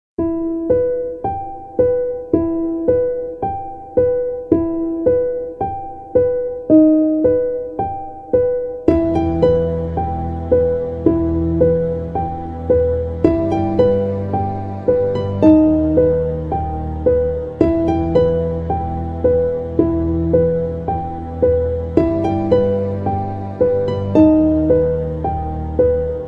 Категория: Музыка из фильмов ужасов